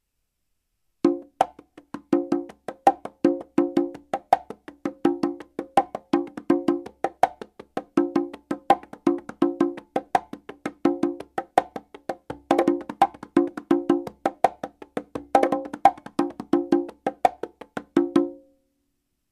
Practice your heel-fingers technique with the following excercises. Start slowly and speed up (very fast!!).